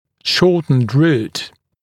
[‘ʃɔːtnd ruːt][‘шо:тнд ру:т]укороченный корень